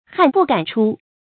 汗不敢出 hàn bù gǎn chū
汗不敢出发音